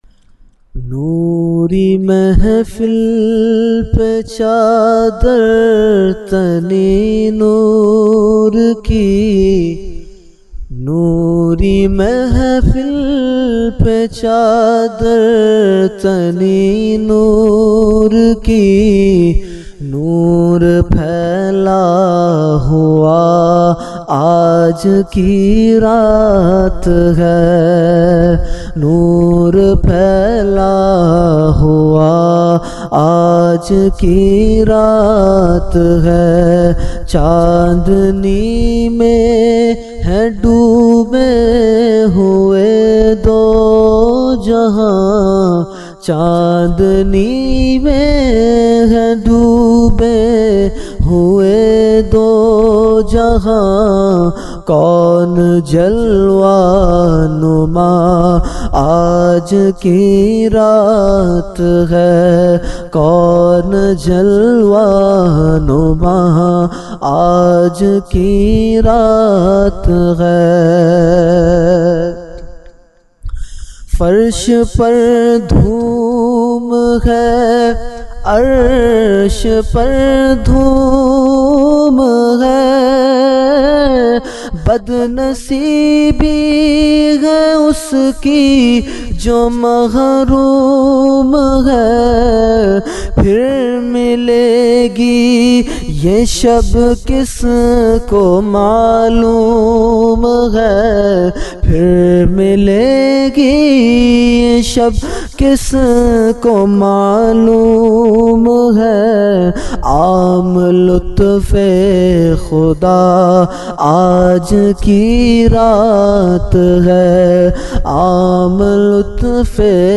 Category : Naat | Language : UrduEvent : Shab e Meraj 2020